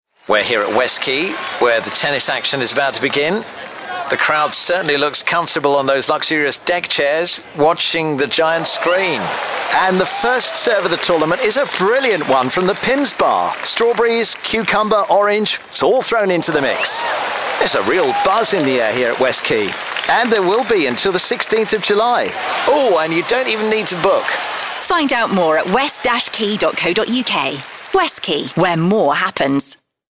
Wimbledon Style Advert.
Wimbledon fortnight leads to an extraordinary number of Wimbledon parodies. Here's one I voiced for Westquay Southampton